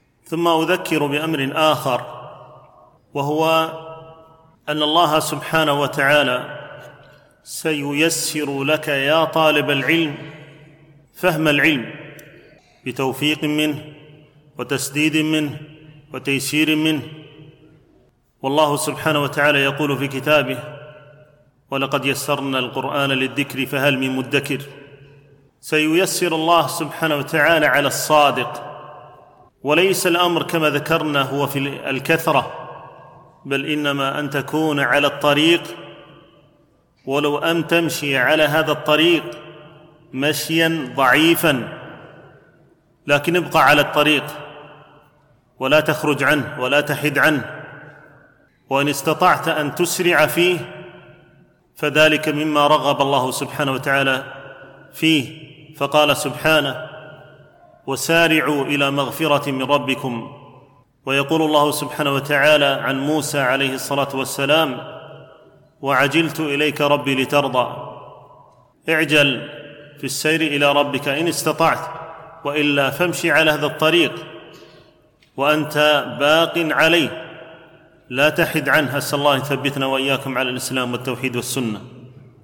التنسيق: MP3 Mono 44kHz 103Kbps (VBR)